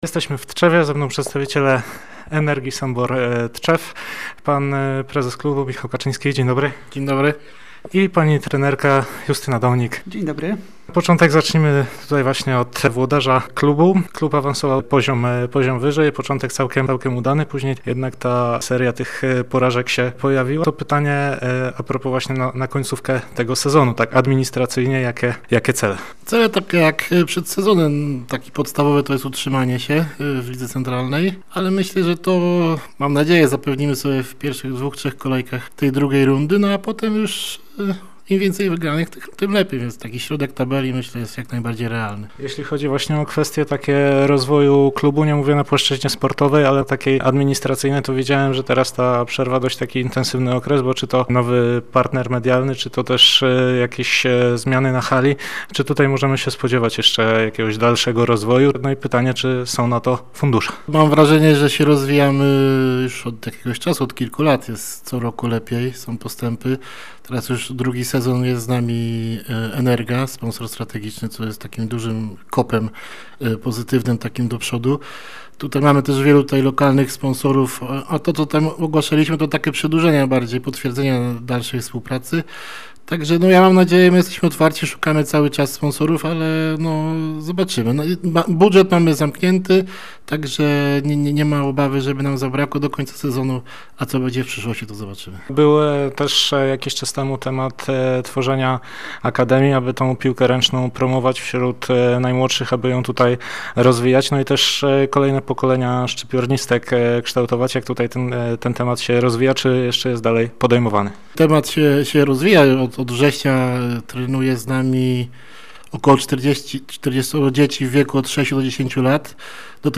powiedział w rozmowie z Radiem Gdańsk